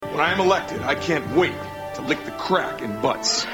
Tags: Pat Finger is Running in Butts Pat Finger Running in Butts Pat Finger funny skit snl skit